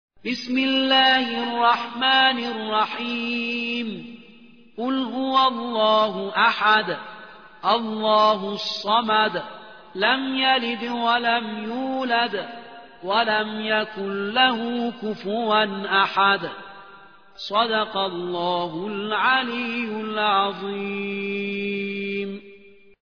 112. سورة الإخلاص / القارئ